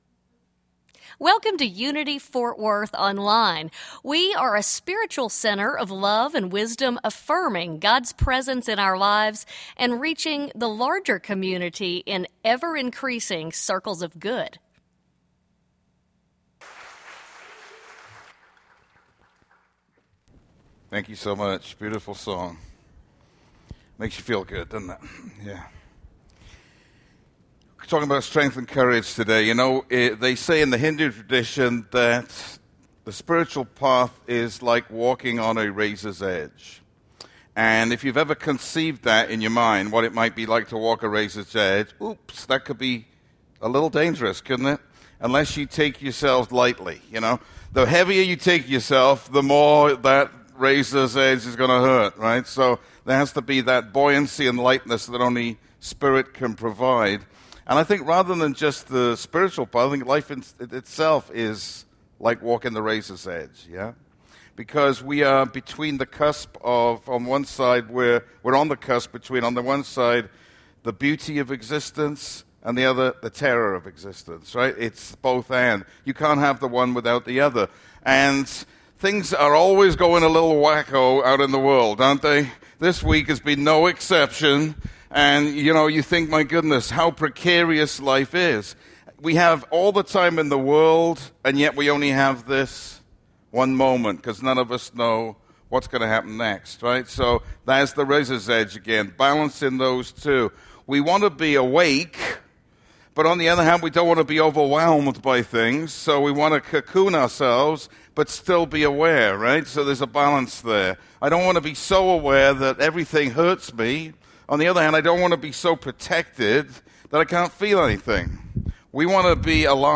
On this Father’s Day 2017 sermon we explore the qualities of strength and courage and how they can serve us well in difficult times.